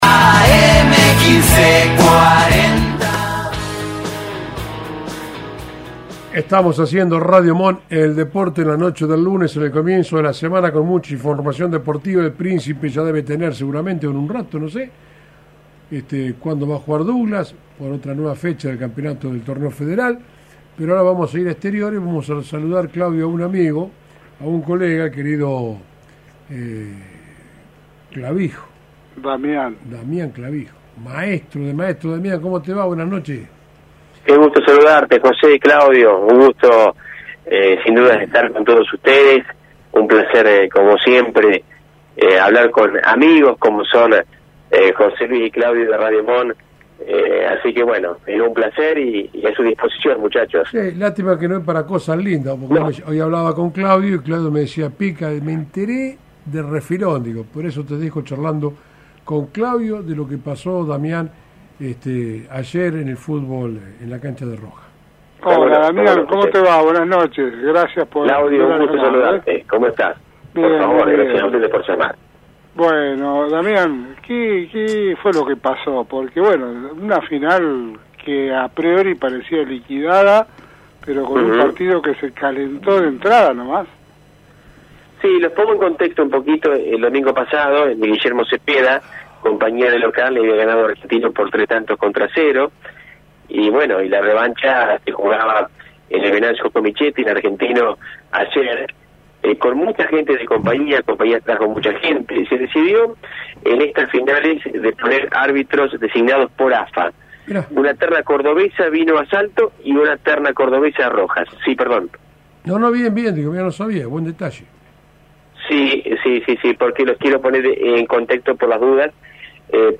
En una entrevista exclusiva en «Radio Mon en el Deporte»